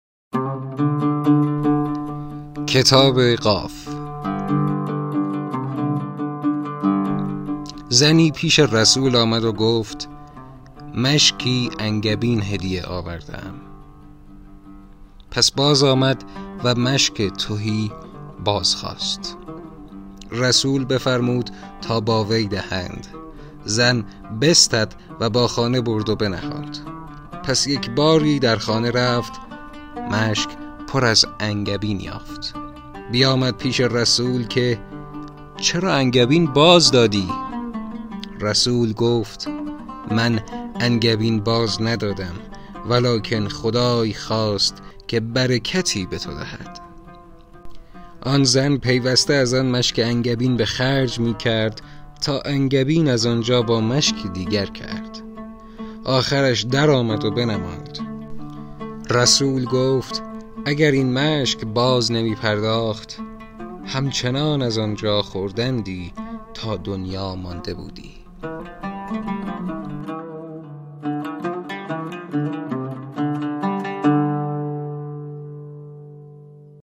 در این بخش متنی از کتاب «شرف‌النبی» انتخاب شده است که با صدای